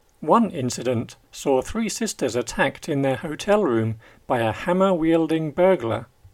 DICTATION 5